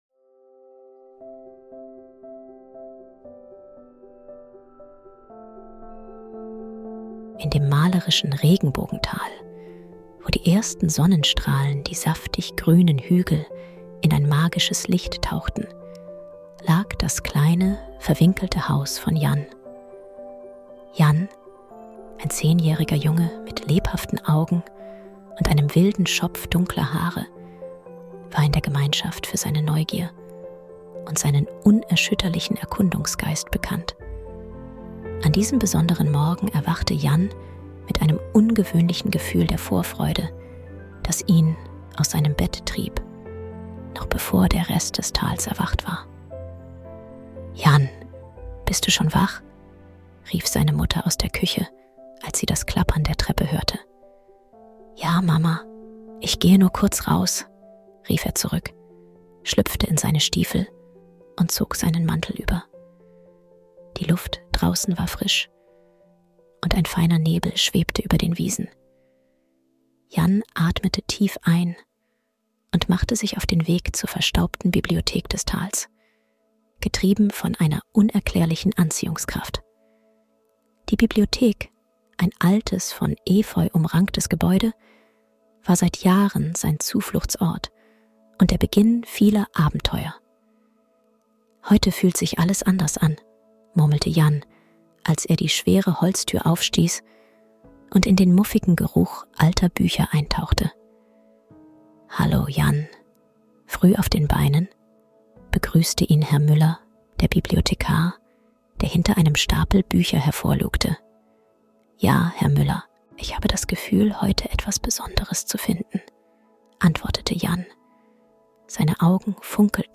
Lasst euch von diesem Hörspiel verzaubern und inspirieren!